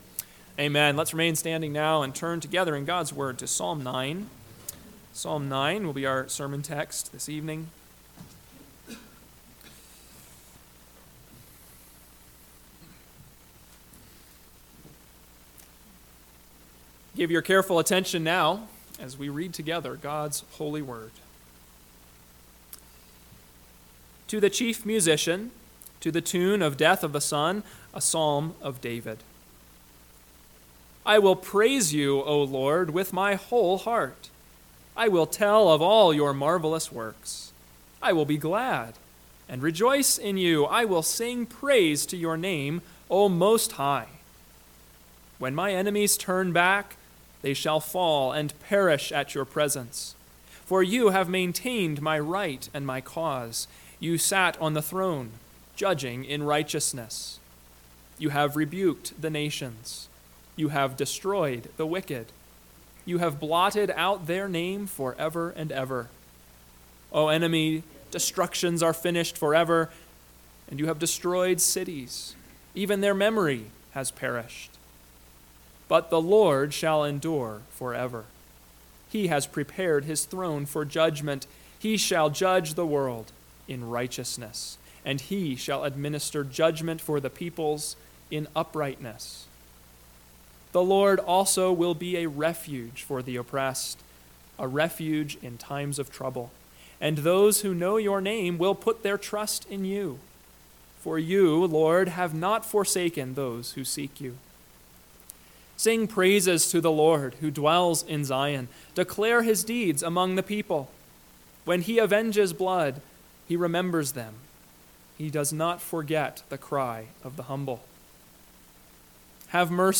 PM Sermon – 4/28/2024 – Psalm 9 – Northwoods Sermons